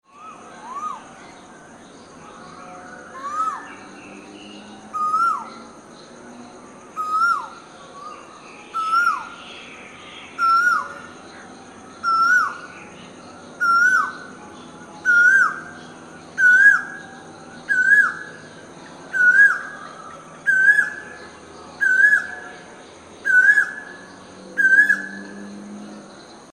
The familiar sound of the Koha  is the song of male bird, which the males sing to attract their females for breeding.
The song of Koha:
Asian-Koel_male_song-1.mp3